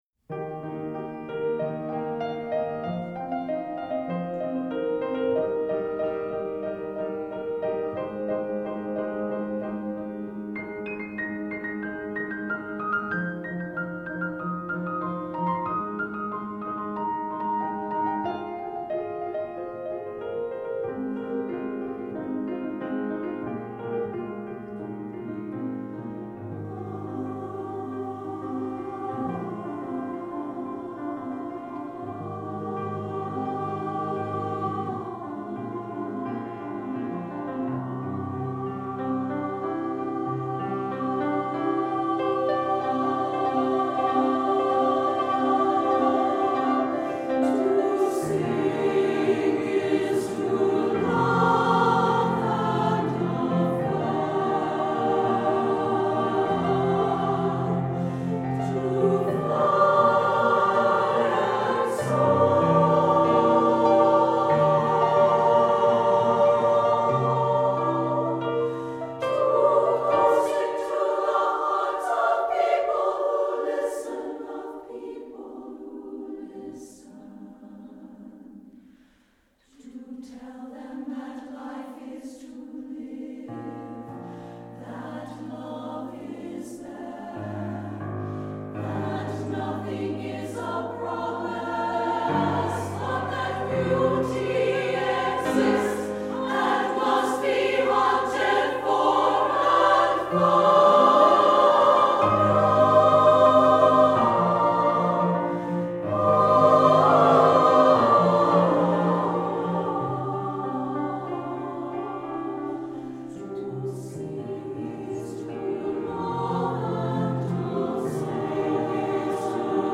for SSAA Chorus and Piano (2003)